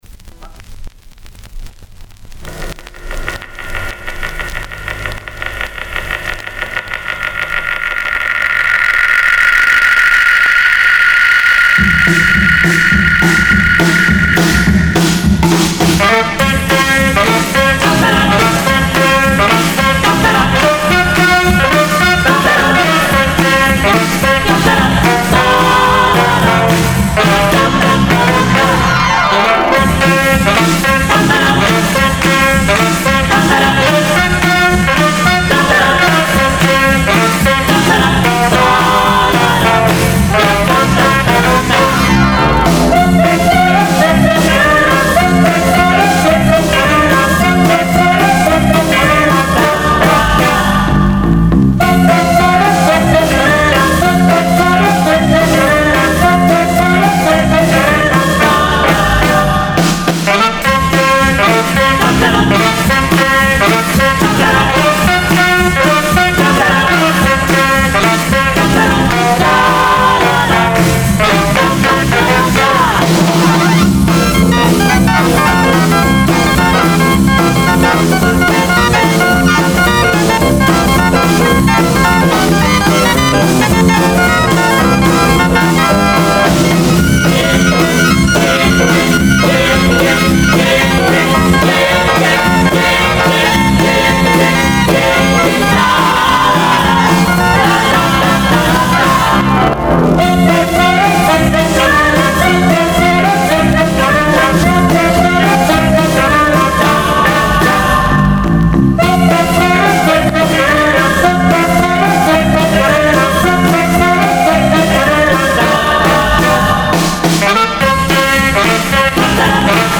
Zenski Kwartet Wokalny